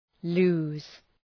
Προφορά
{lu:z}